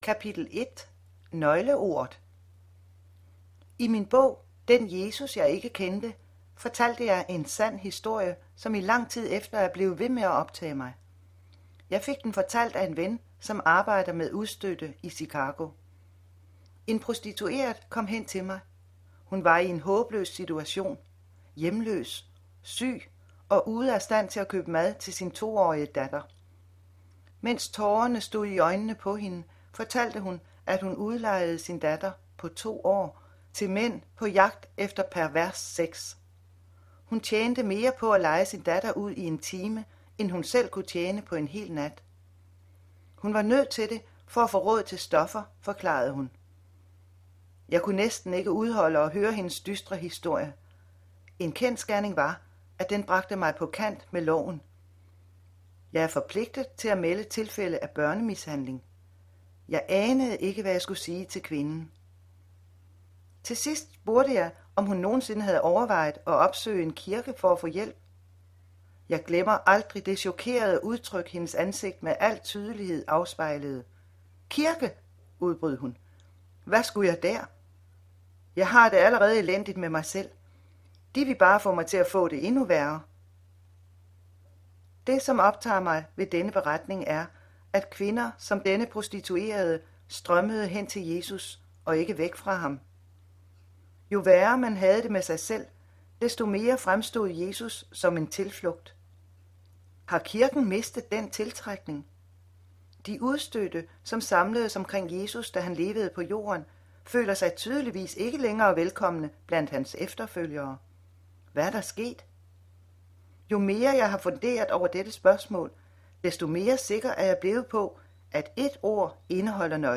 Den Nåde Jeg ikke Kendte (lydbog)